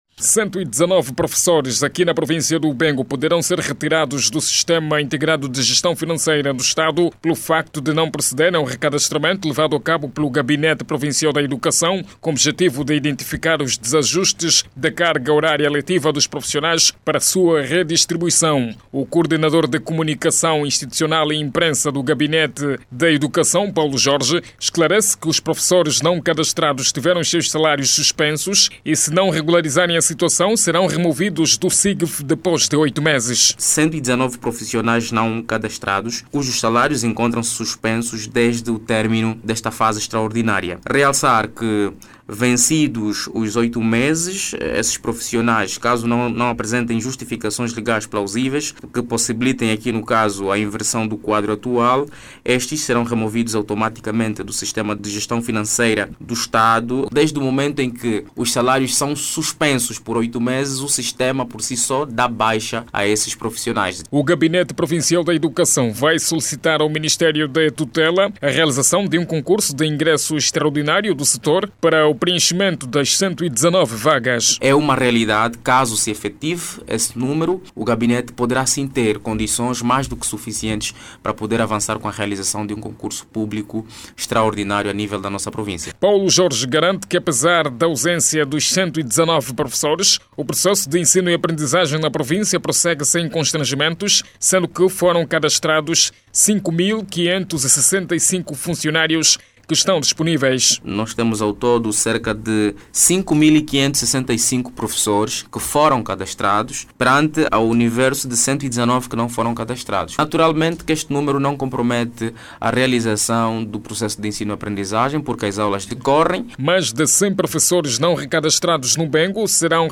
Mais de 100 professores que não foram recadastrados na Província do Bengo, tiveram os seus salários suspensos, e poderão ser removidos do sistema integrado de gestão financeira do Estado. O Gabinete provincial da Educação, garante que, vai solicitar o Ministério, a realização de um concurso extraordinário para o preenchimento de vagas. Clique no áudio abaixo e ouça a reportagem